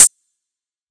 Closed Hats
hihat (bouncy).wav